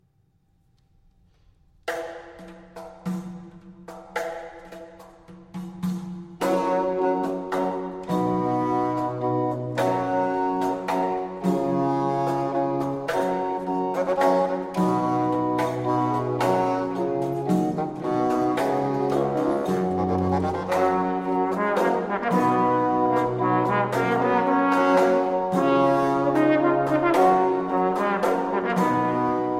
Posaune